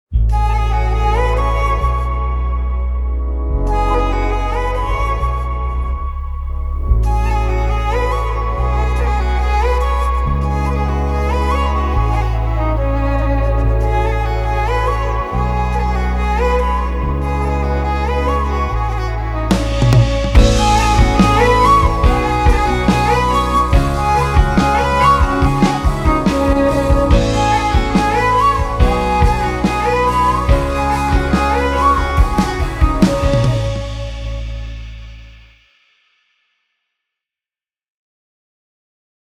We made it like an official one with proper sound balance.